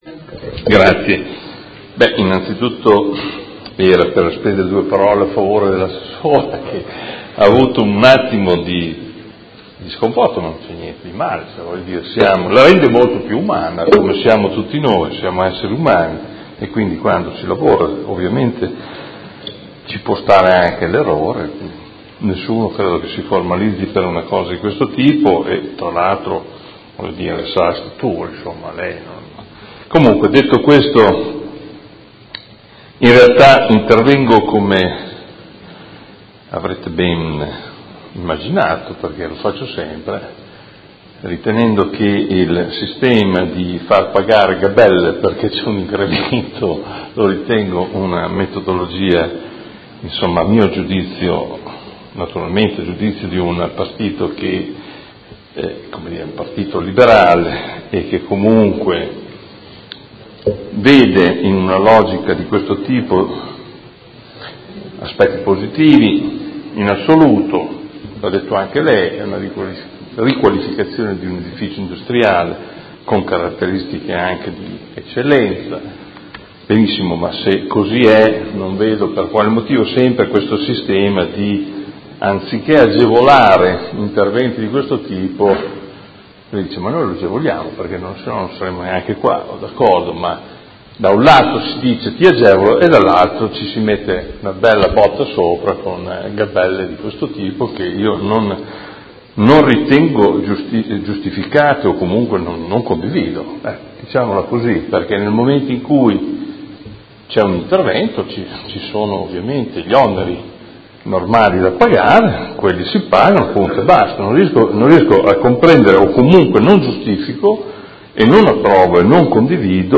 Seduta del 13/07/2017 Dibattito.